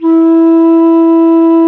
Index of /90_sSampleCDs/Roland LCDP04 Orchestral Winds/FLT_Alto Flute/FLT_A.Flt nv 3
FLT ALTO F06.wav